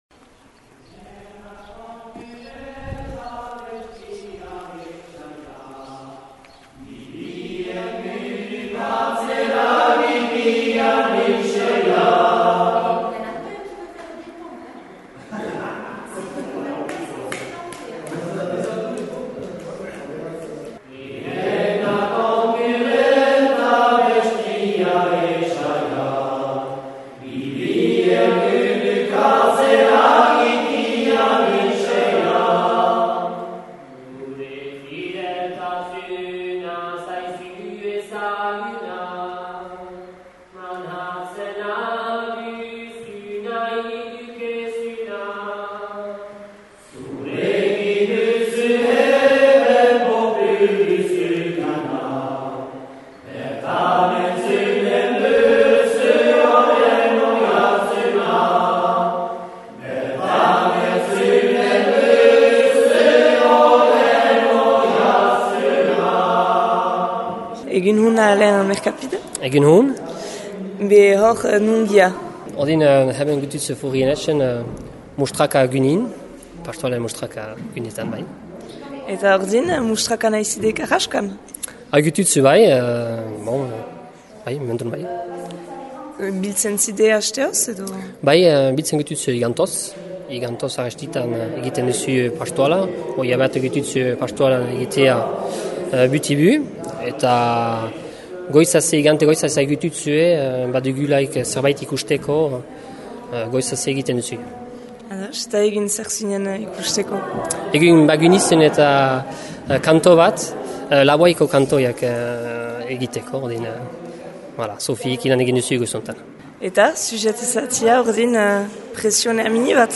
Erreportajea: